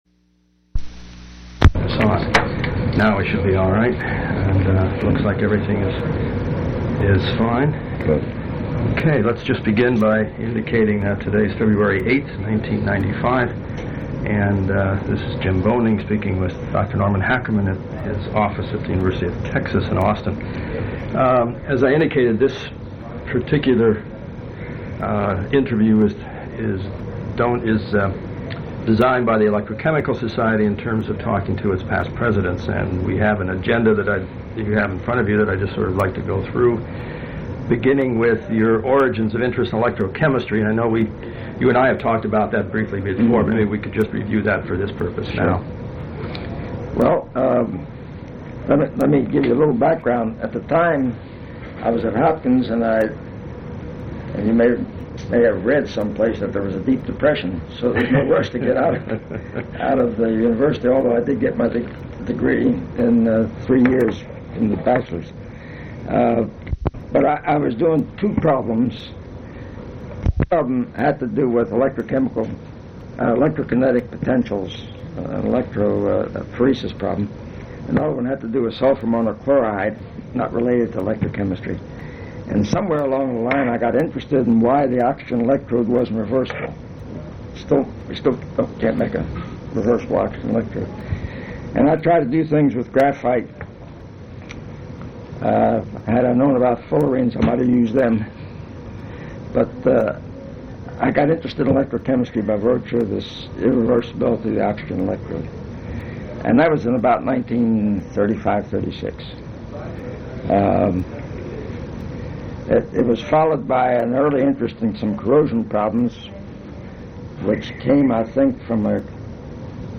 Oral history interview with Norman Hackerman
Place of interview University of Texas at Austin